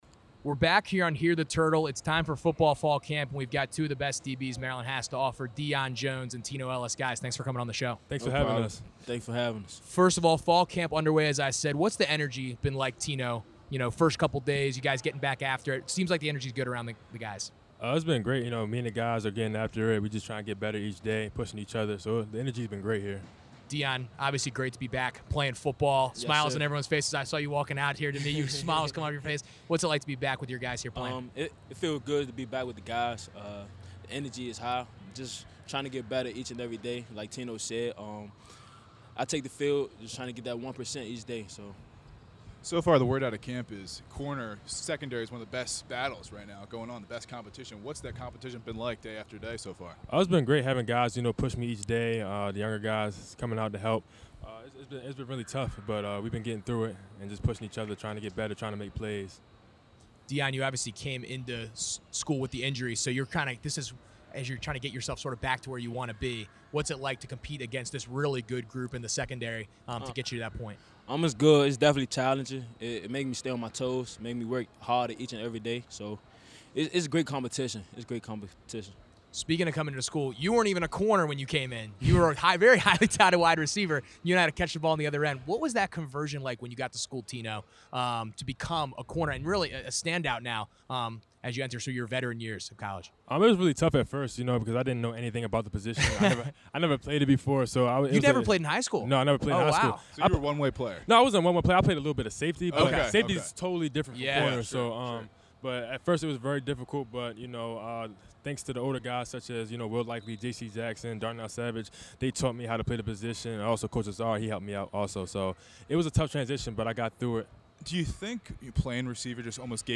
The sound of cracking pads? Whistles blaring in the afternoon sun?